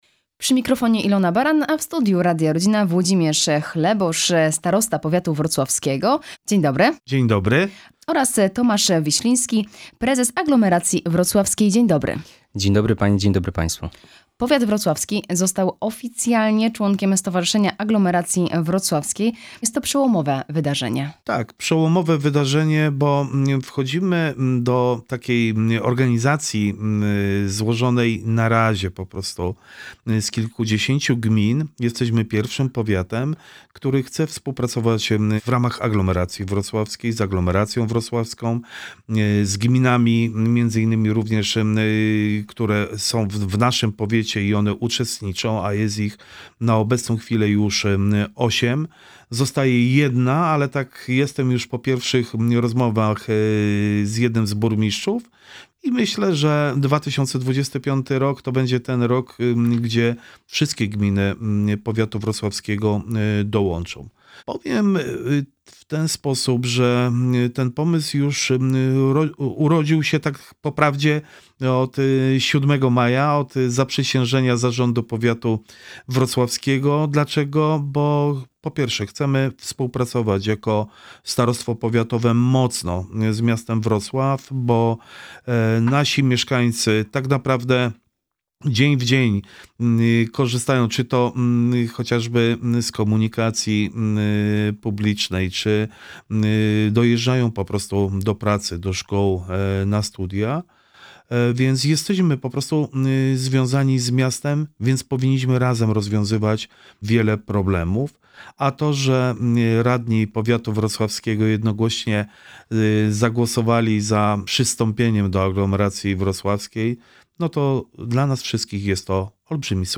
W studiu Radia Rodzina Włodzimierz Chlebosz
Cała rozmowa: